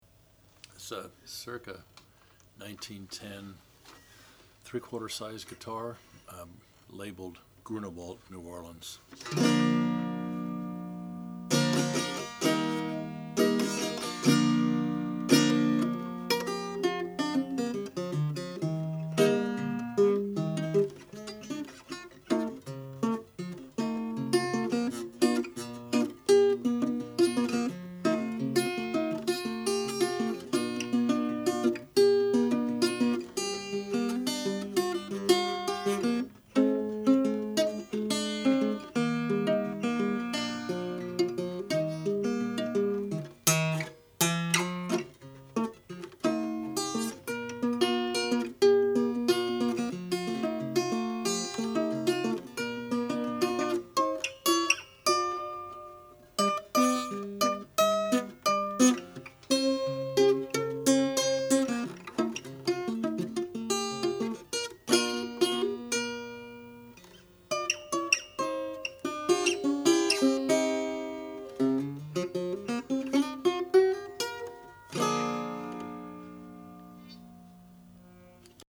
The ladder-braced top is made from spruce and bound in colorful marquetry and white celluloid, echoed at the sound hole.
The 'terz' is tuned a third higher than A440. The sound is a bit boxy due to its small size, but when played with a pick it can really cut!